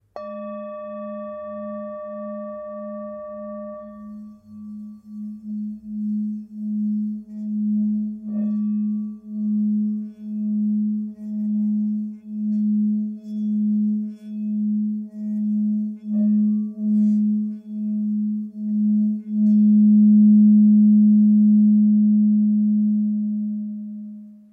Whipped Tibetan bowl Go weighing 906 g, including chopstick
You can listen to the sound of the Tibetan bowl Chuto
Type of bowl Hammered